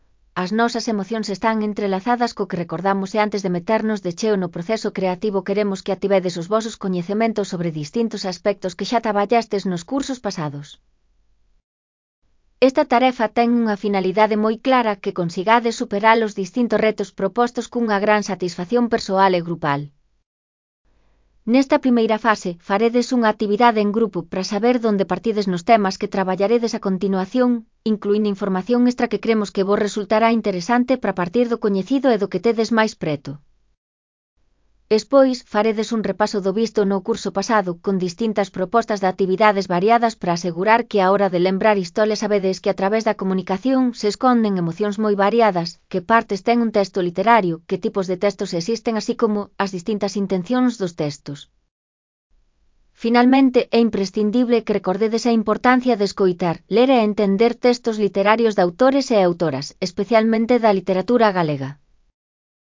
Elaboración propia (Proxecto cREAgal) con apoio de IA, voz sintética xerada co modelo Celtia..